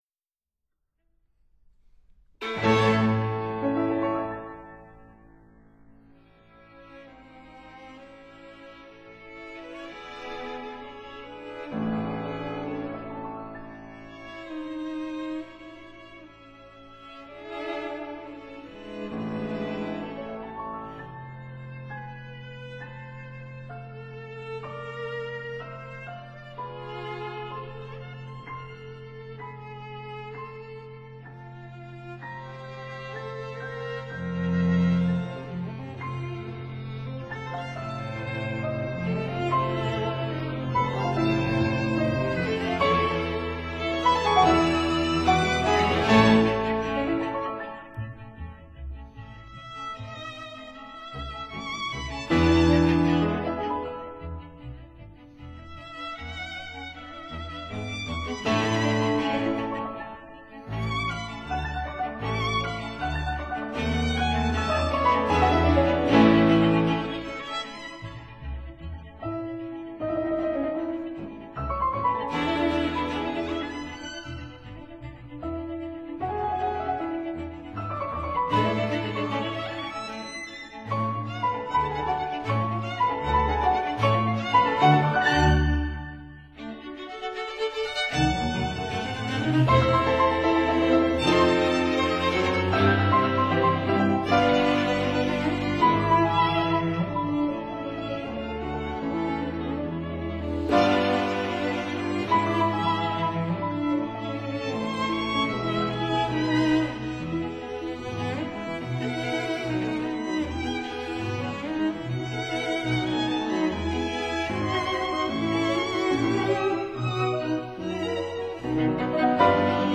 violin
cello
viola
double bass